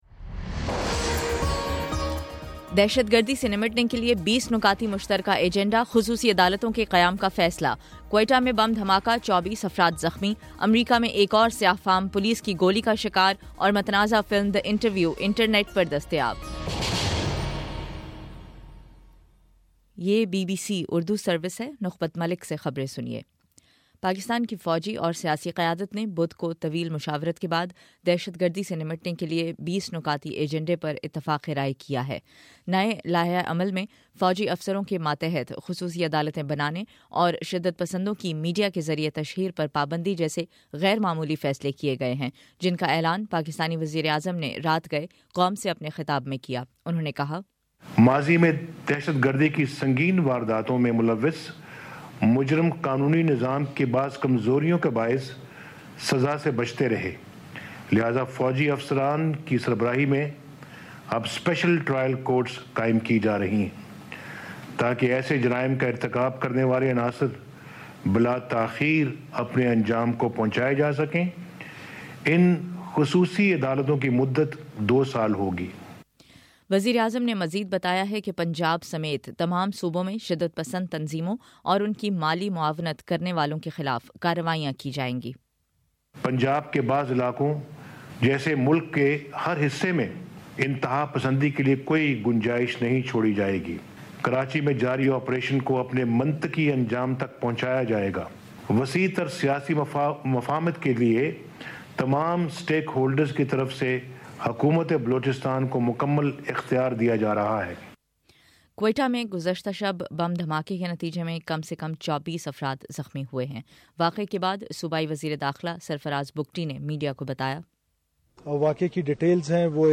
دسمبر25: صبح نو بجے کا نیوز بُلیٹن